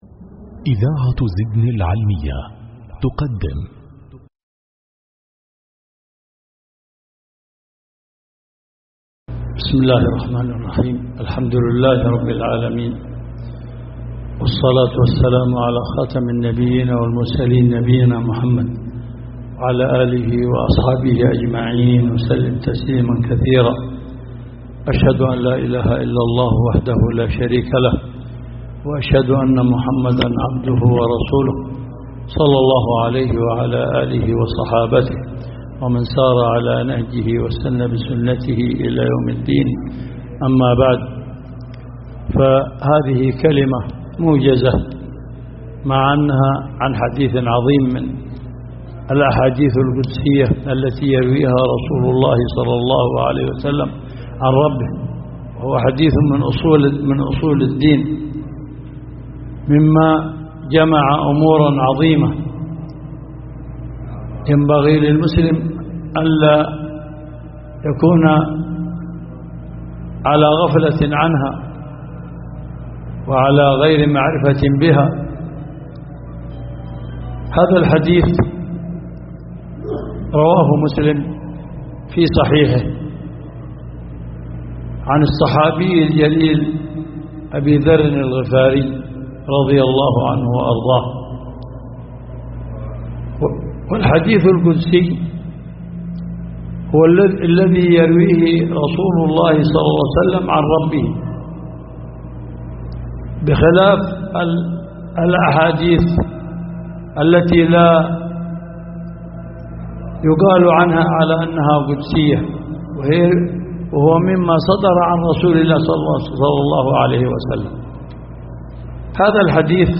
محاضرة شرح حديث :( يا عبادي إني حرمت الظلم على نفسي ...
جامع القرن بصامطة